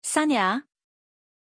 Aussprache von Sanya
pronunciation-sanya-zh.mp3